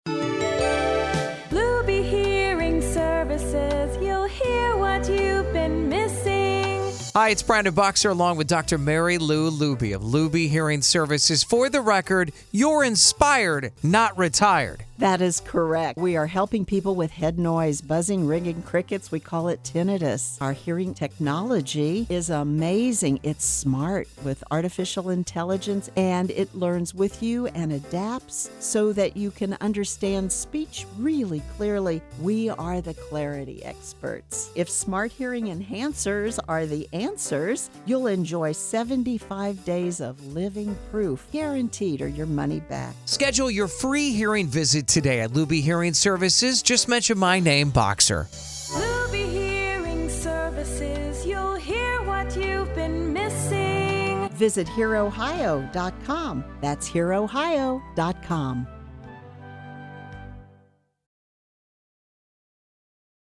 Radio Spot About Tinnitus